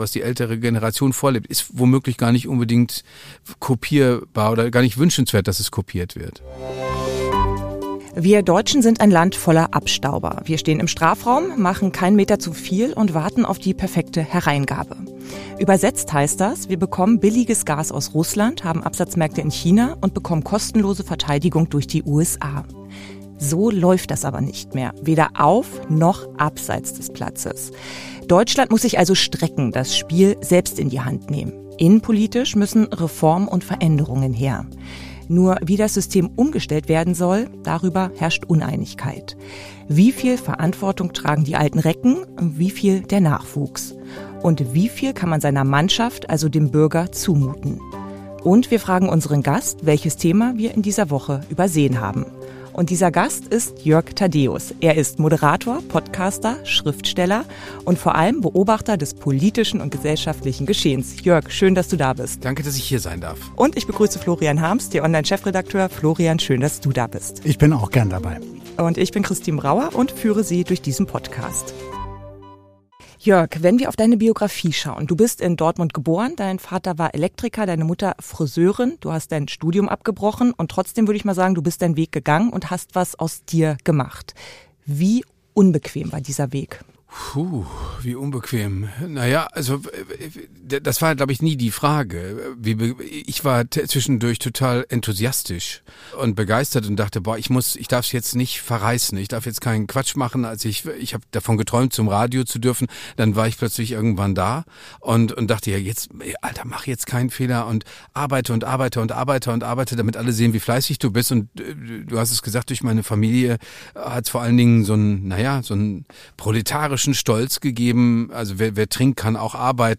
Tagesanbruch – die Diskussion Was fehlt Deutschland gerade?